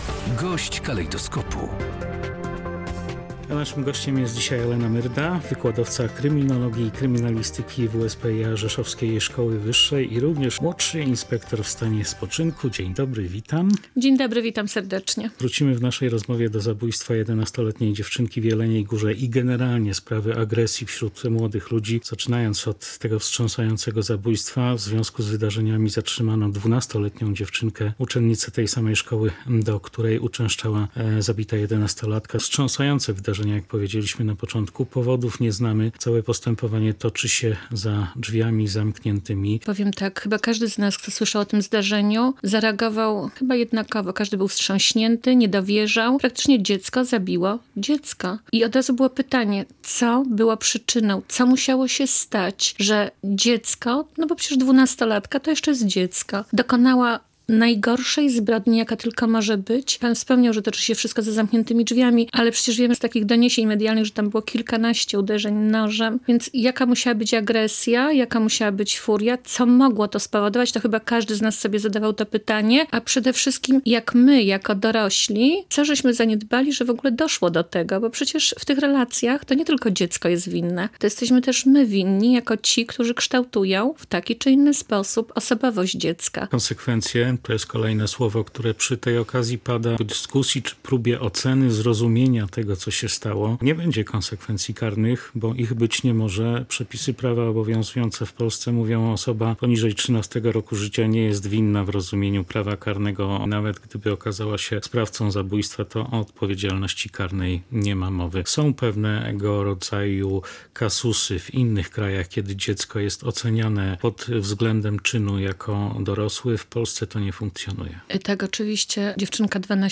Gość dnia • WSPiA Rzeszowska Szkoła Wyższa prowadzi Podkarpacką Młodzieżową Akademię Prawa i Bezpieczeństwa, program skierowany do uczniów szkół